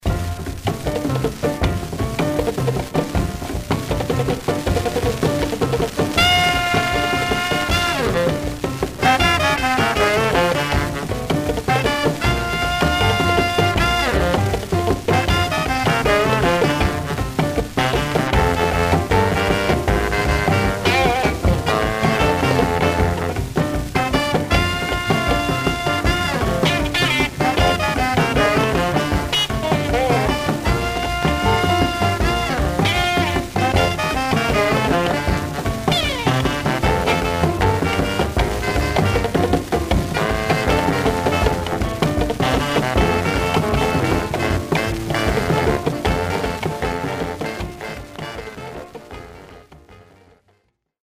Some surface noise/wear
Mono
R&B Instrumental